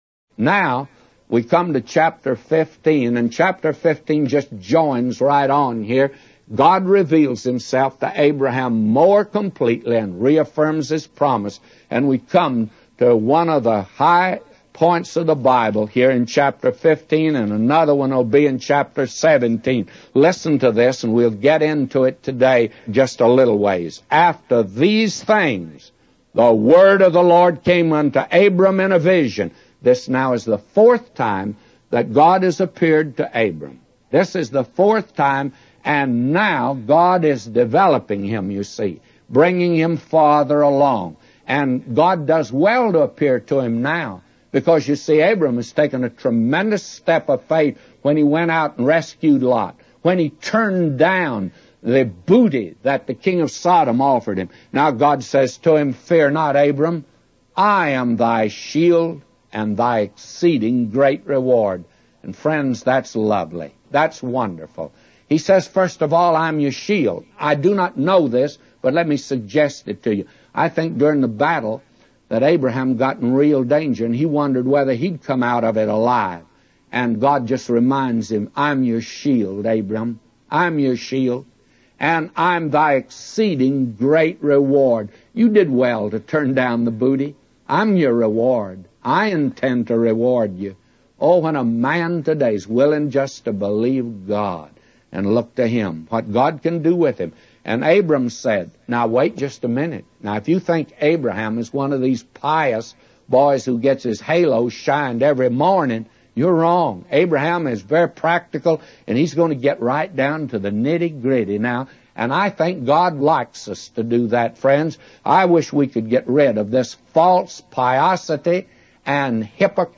A Commentary By J Vernon MCgee For Genesis 15:1-999